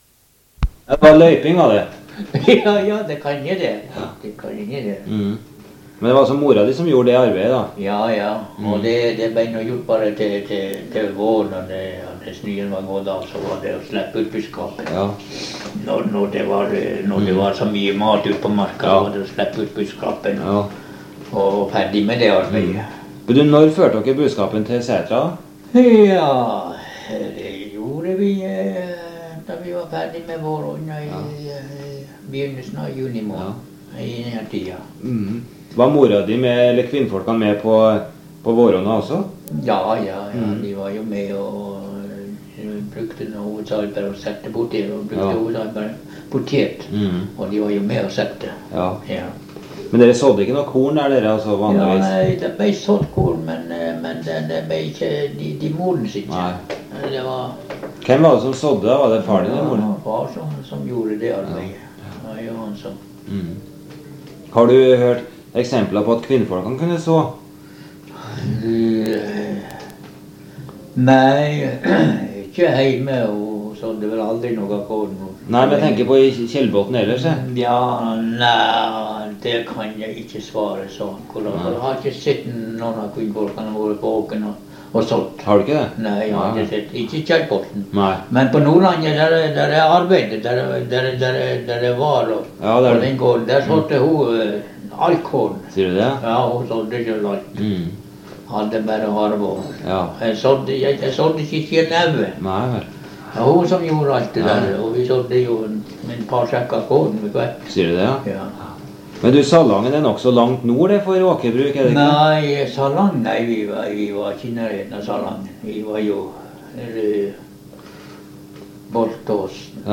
Sted: Ballangen, Tjellemarka